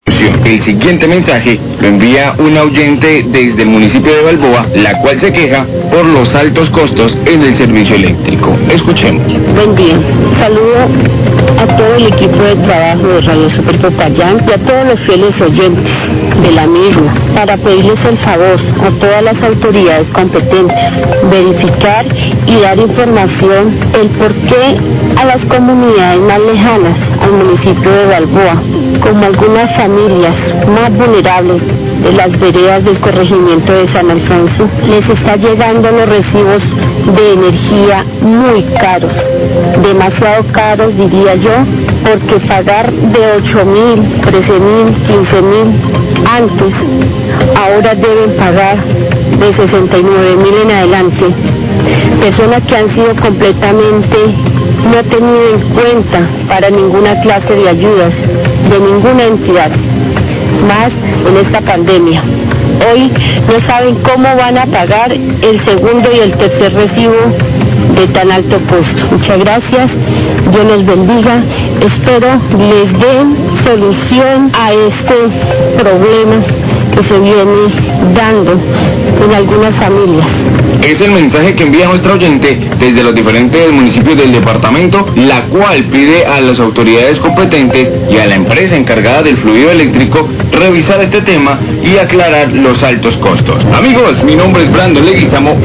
Radio
Desde Balboa una oyente se queja por el alto costo en el servicio de energía en el corregimiento San Alfonso; de 8 mil pesos ahora llega facturas de energía de 69 mil pesos, no han tenido ayudas en pandemia y no saben cómo van a pagar los recibos.